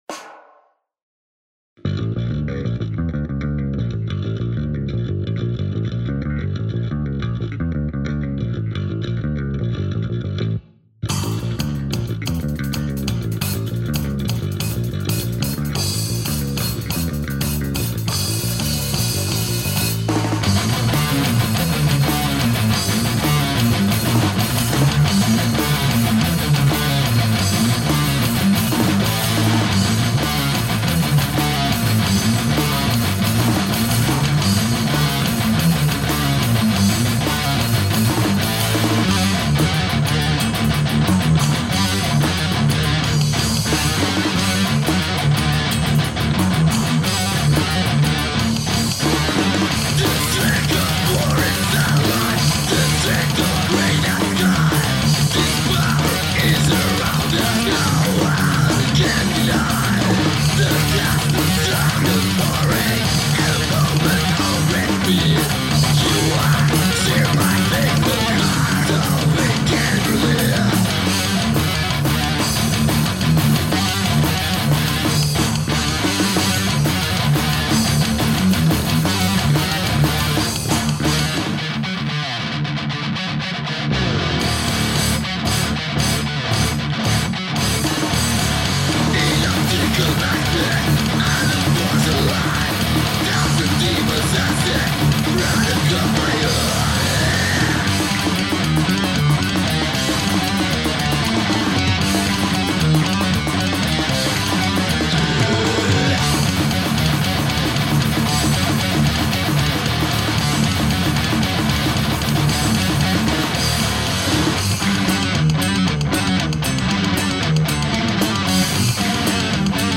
Solos has delay and reverb..